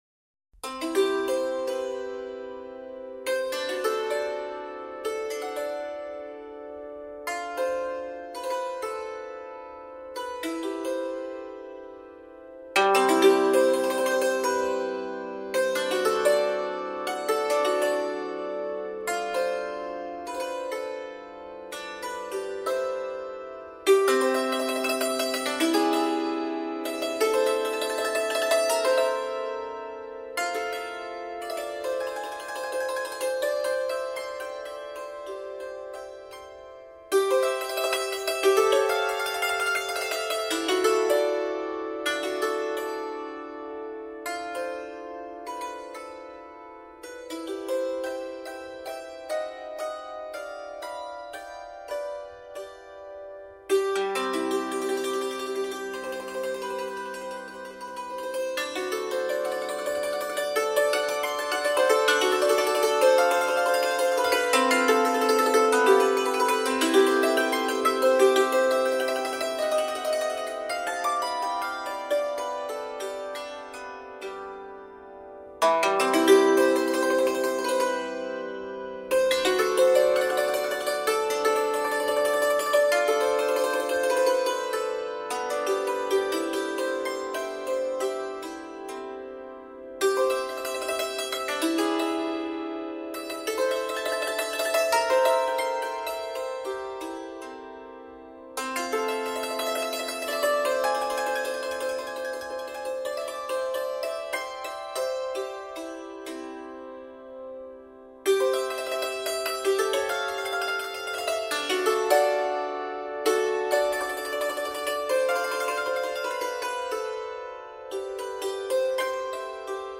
Dulcimer Artist and Story Teller